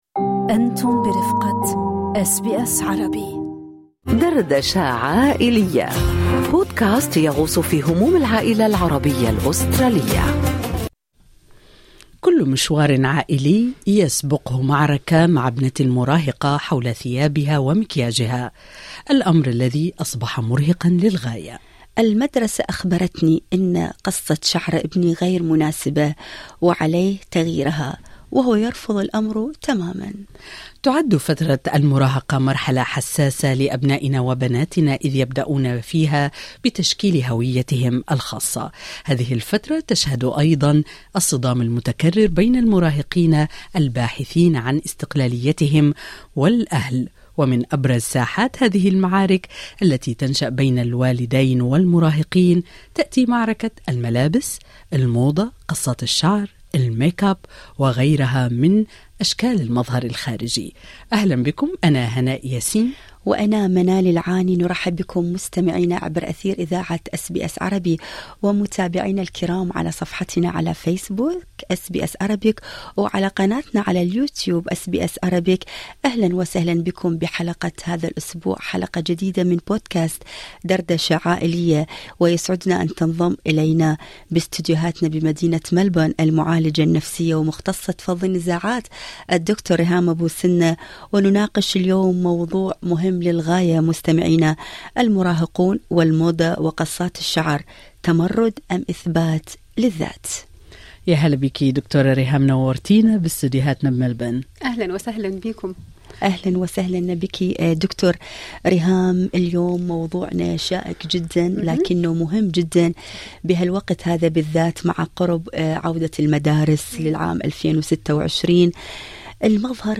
تنضم الينا المعالجة النفسية ومختصة فض النزاعات